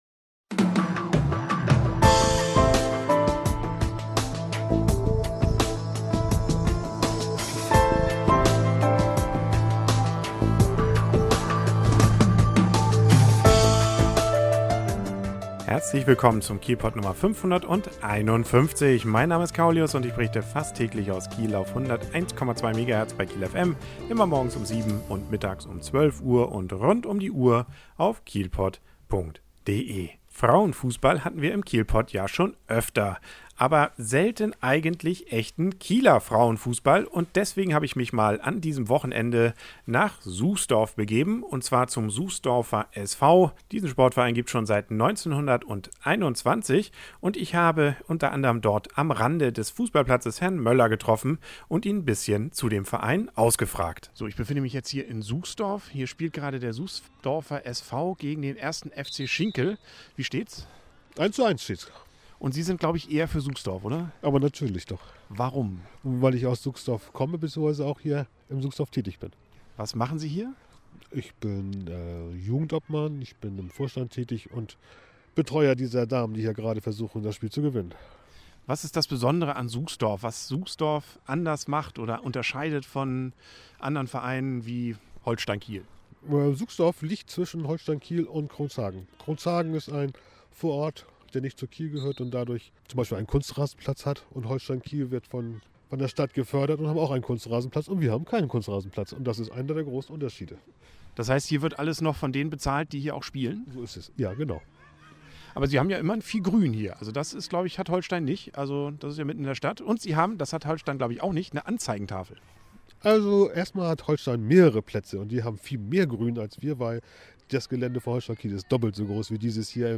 Bericht über Frauenfußball beim Suchsdorfer SV mit Interviews am Rande des Spiels Suchsdorfer SV gegen 1. FC Schinkel (Kreisklasse Frauen A West) – Endergebnis 3:2!